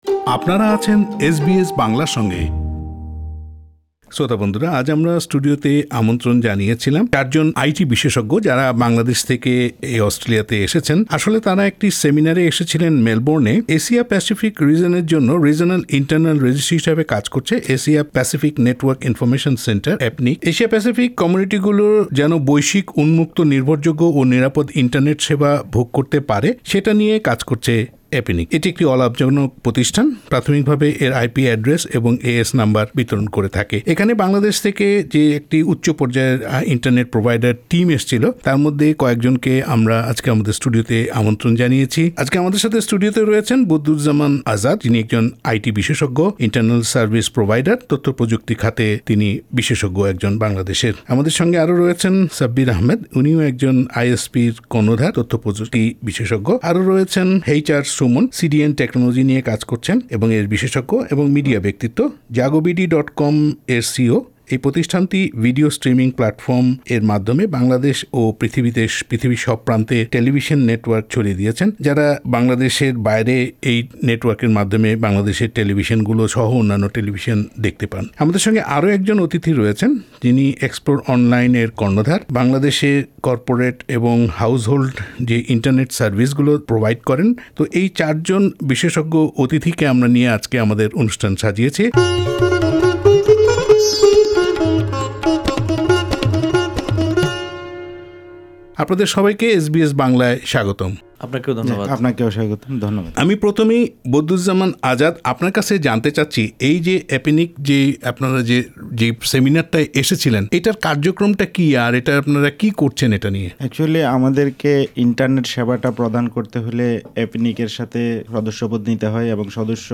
ওই দলের কয়েকজনের সঙ্গে কথা হয় বাংলাদেশের আইটি ও এর ভবিষ্যৎ নিয়ে। সাক্ষাৎকারগুলো শুনতে উপরের লিংকটিতে ক্লিক করুন।